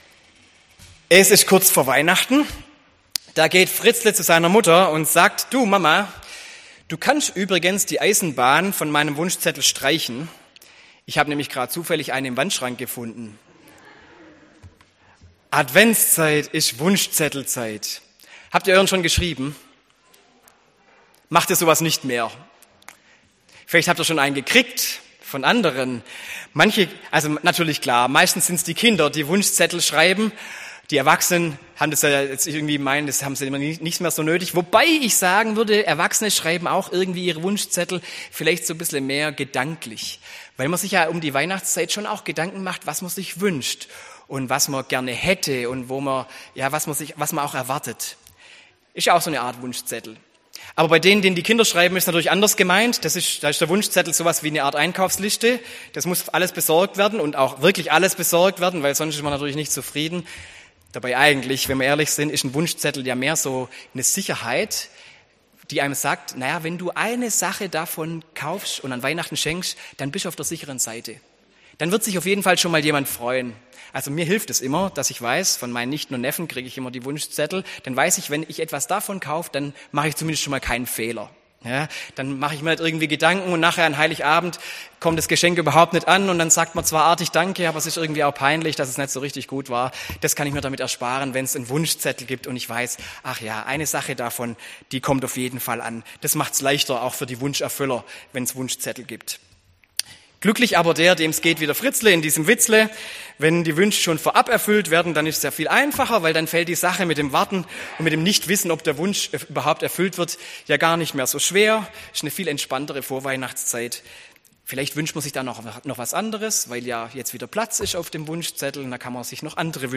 Predigt am 1. Advent